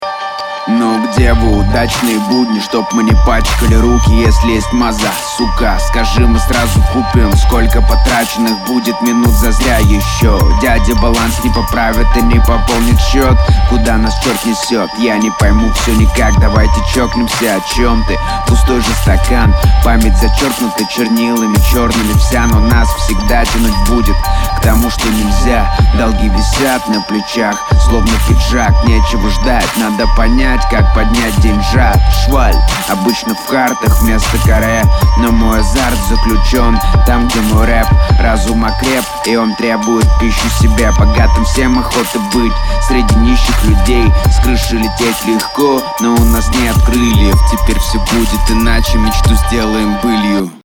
Внушительное исполнение на неплохой текст.
Качество хромает, но по тексту претензий нет, он даже интересен, впечатление хорошее
Подача басовая и уверенная, есть некий потенциал. Стоит усложнять тематику трека, о слишком очевидных и простых вещах рассуждаешь